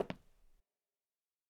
FootstepW5Right-12db.wav